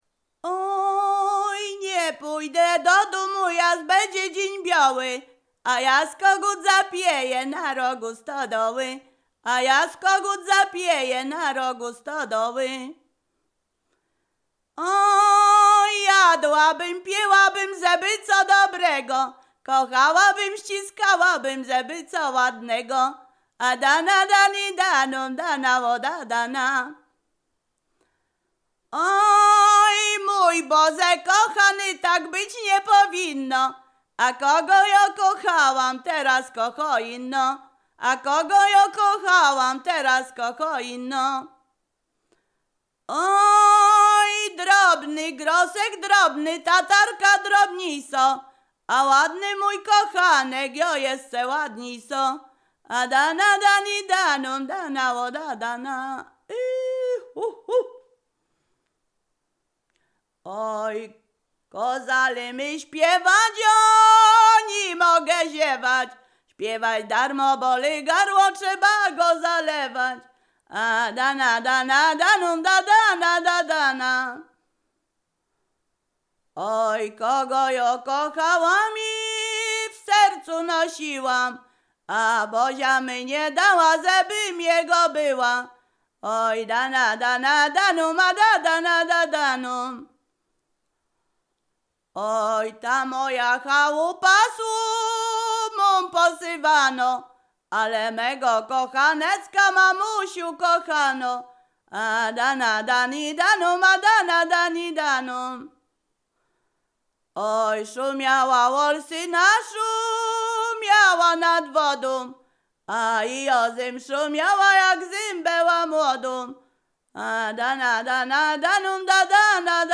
Ziemia Radomska
Przyśpiewki
liryczne miłosne weselne wesele przyśpiewki